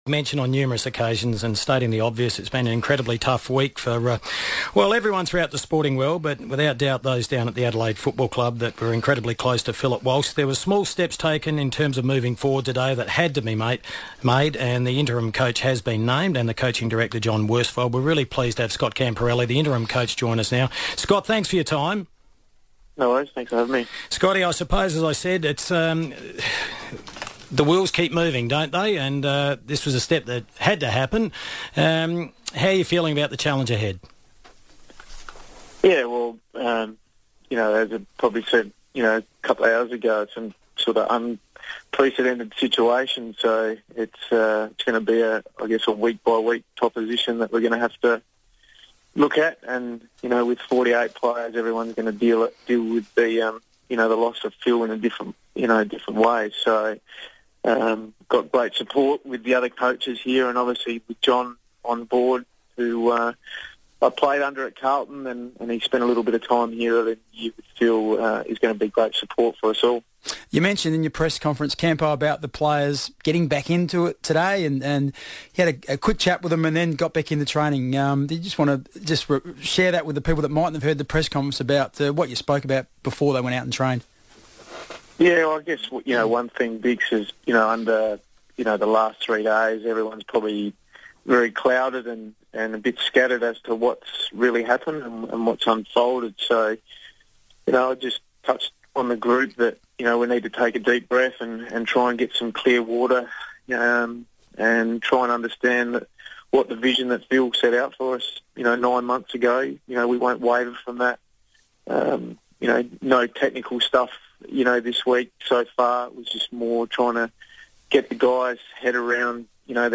Scott Camporeale on FIVEaa